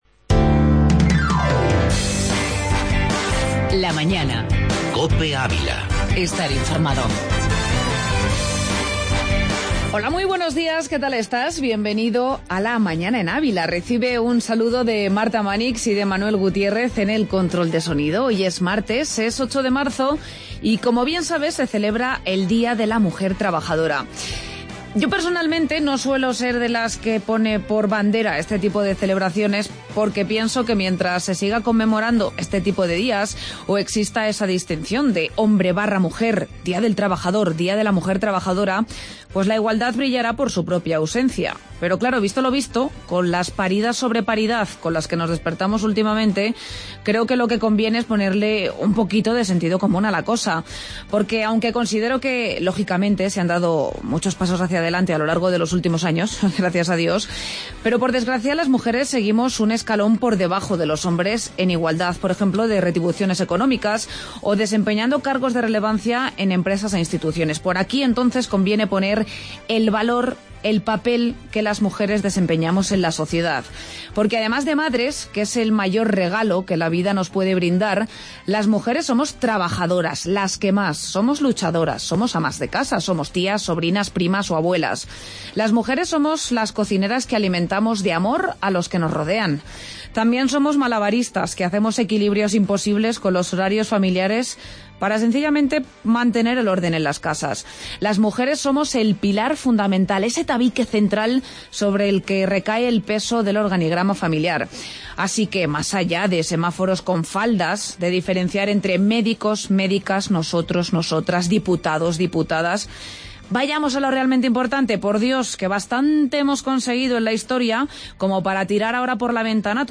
AUDIO: Entrevista Día de la Mujer e Ilusionadas